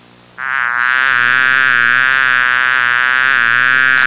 Big Mosquito Sound Effect
Download a high-quality big mosquito sound effect.
big-mosquito.mp3